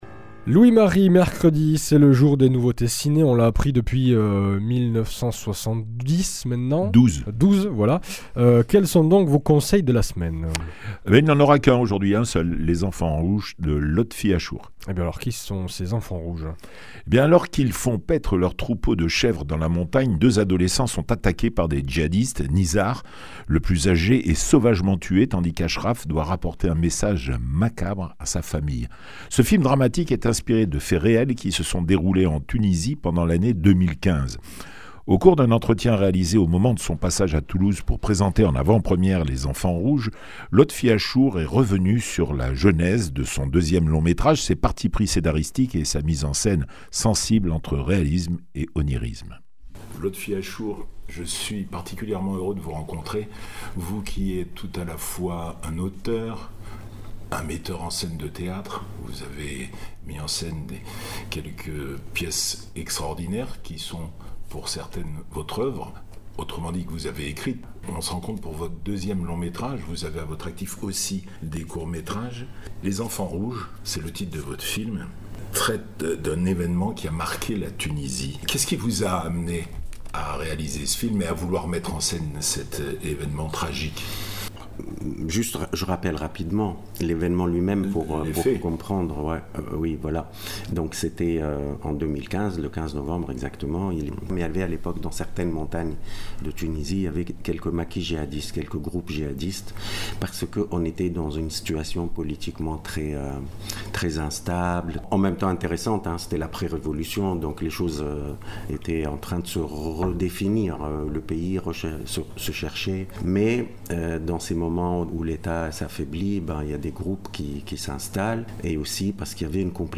Au cours d’un entretien réalisé au moment de son passage à Toulouse pour présenter en avant-première son deuxième film, Lotfi Achour est revenu sur la genèse de son deuxième long métrage, ses partis pris scénaristiques et sa mise en scène se